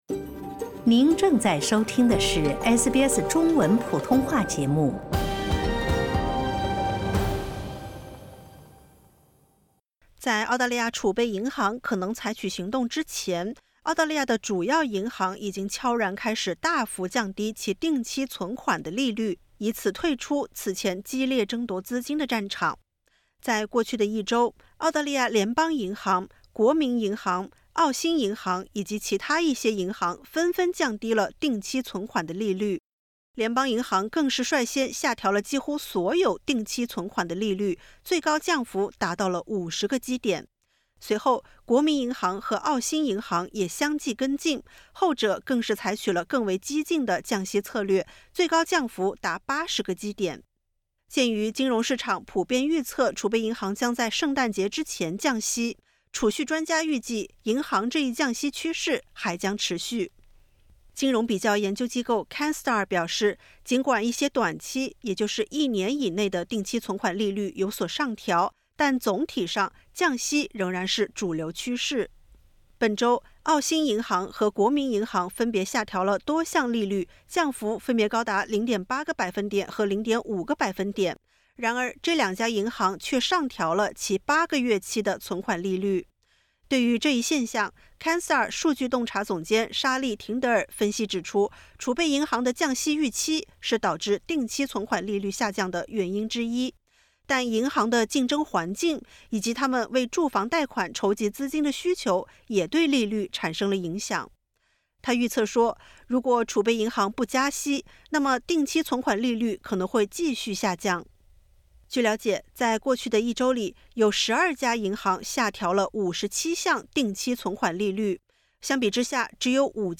近期，多家澳大利亚主要银行开始下调其定期存款利率，这一举动被视为即将降息的信号，同时也提醒储户需关注其账户变动。点击 ▶ 收听完整报道。